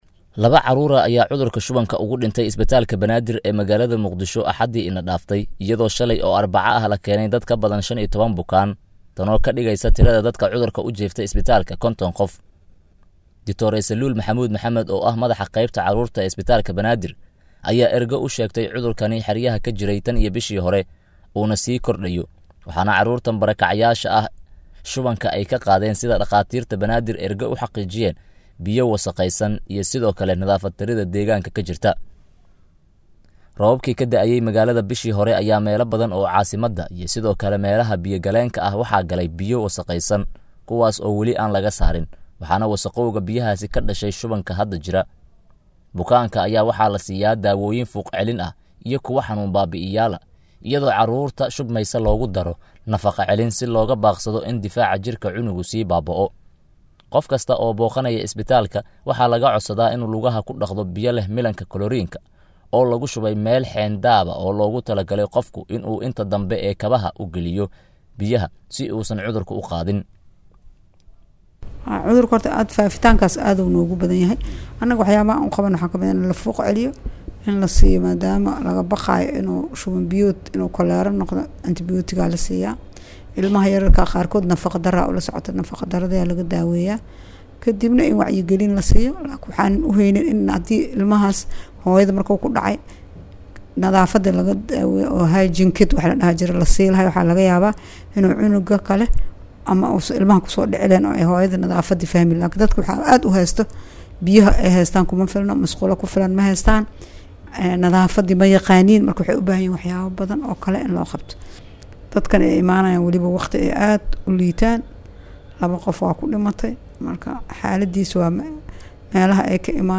Warbixin-Carruur-barakacayaal-ah-oo-shuban-uu-soo-ritey.mp3